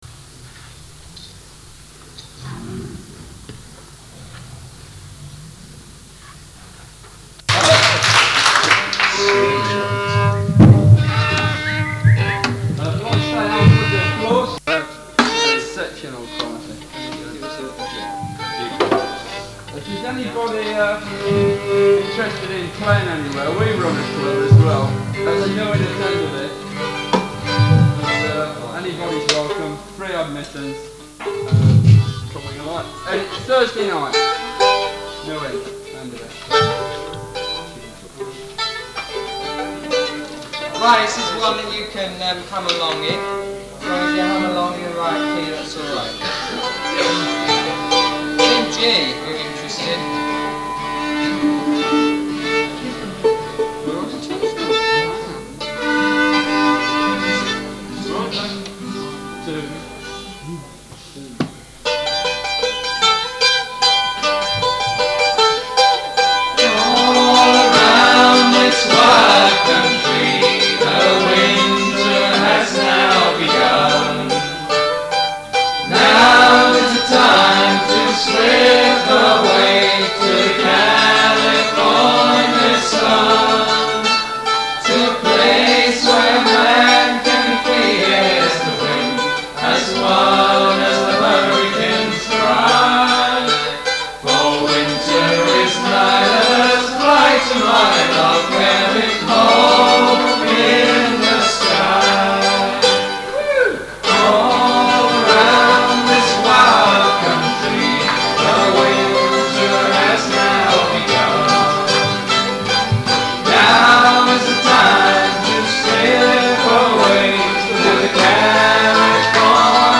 Guitars, Bass, Percussion, Vocals
Mandolin, Bouzouki, viola, Vocals
Bodhran, Guitar, Vocals
Pipes, Whistle, guitar, Vocals
Fiddle, Vocals